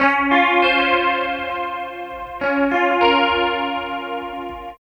60 GUIT 2 -L.wav